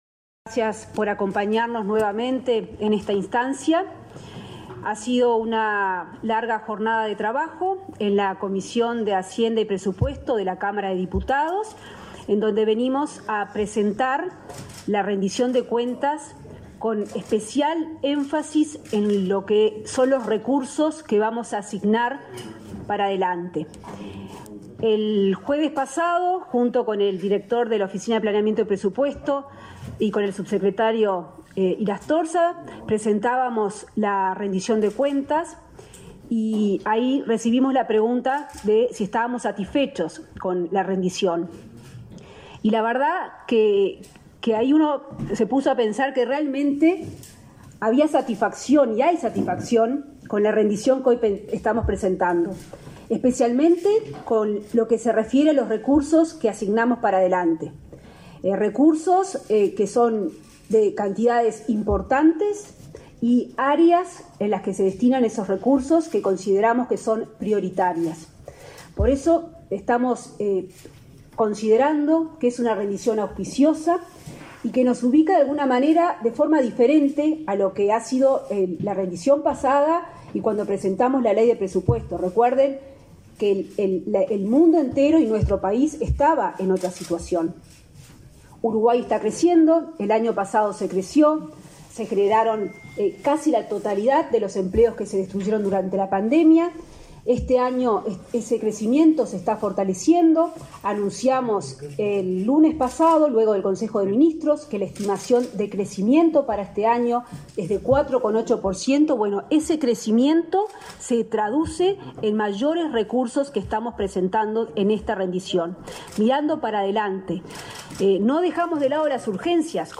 Conferencia de prensa de la ministra de Economía y Finanzas, Azucena Arbeleche